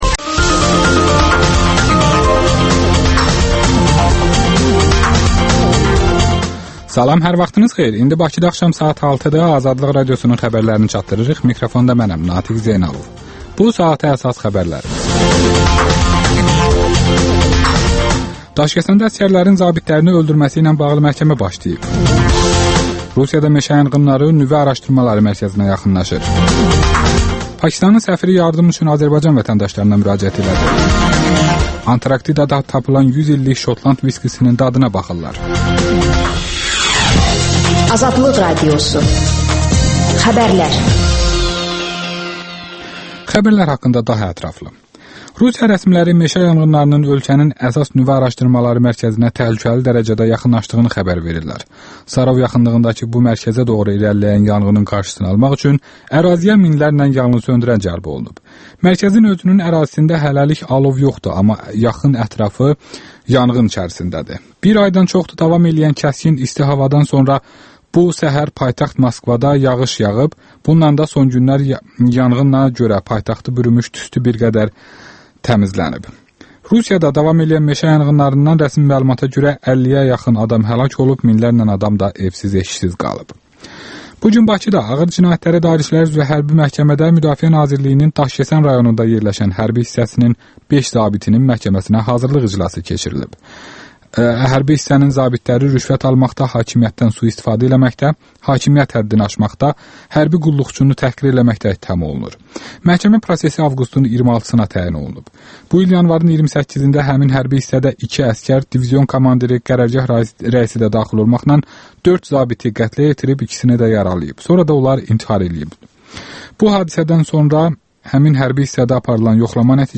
İşdən sonra - yazıçı Rafiq Tağı ilə söhbət